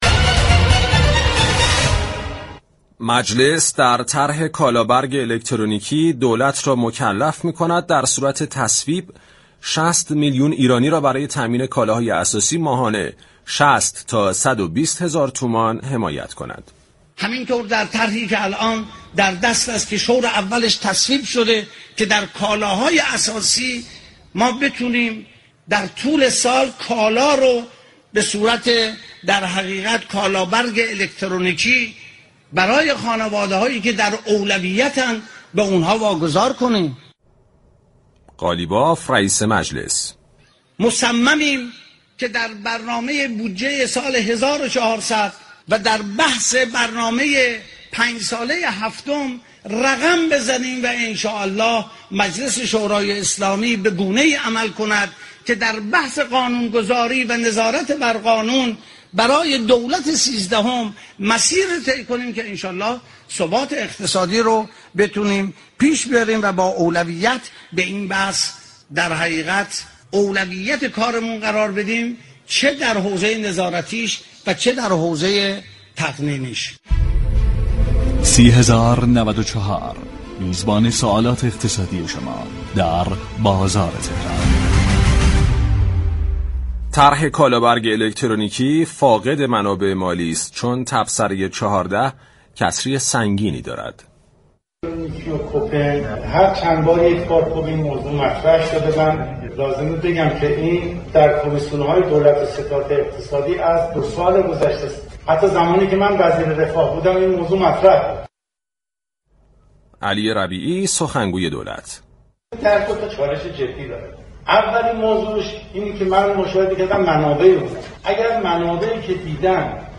امیرحسین قاضی‌زاده هاشمی در گفتگو با بازار تهران درباره اظهارات اخیر نمایندگان دولت درباره طرح كالابرگ مصوبه مجلس شورای اسلامی گفت: ما در آخر متوجه نشدیم كه دولت چند نماینده دارد.